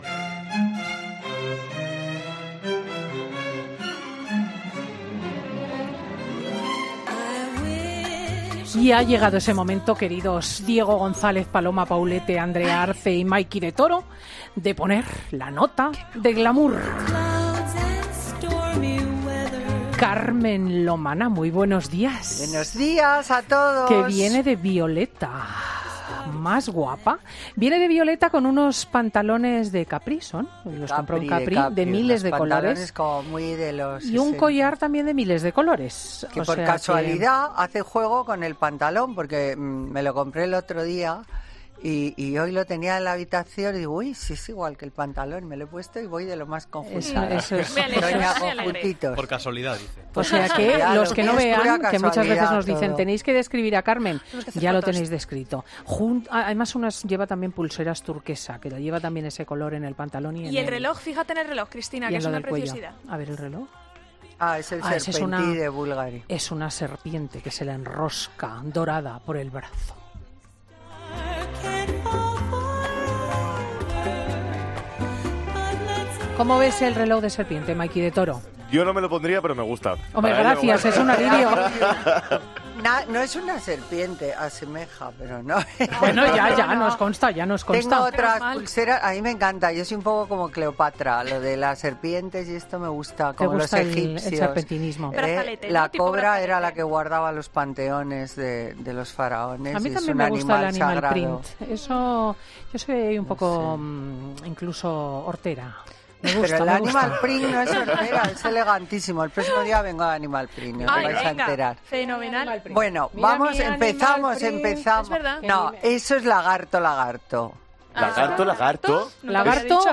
AUDIO: Escuha todas las consultas de los oyentes a Carmen Lomana